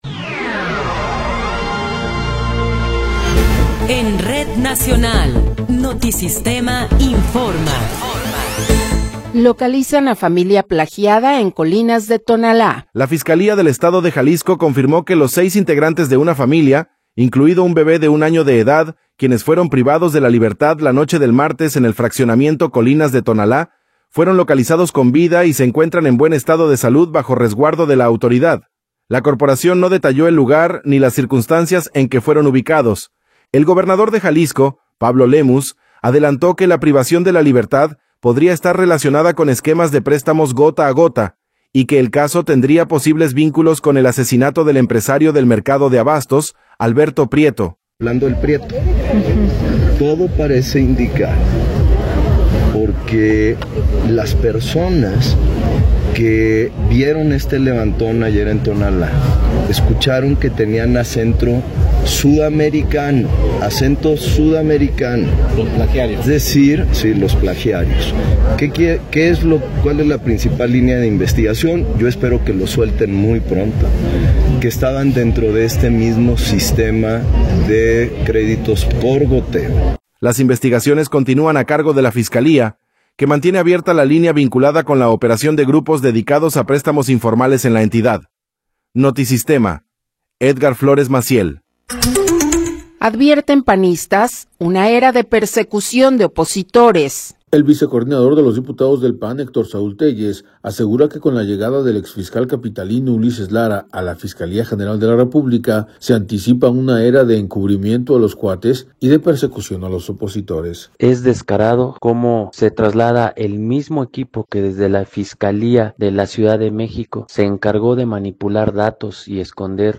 Noticiero 15 hrs. – 6 de Enero de 2026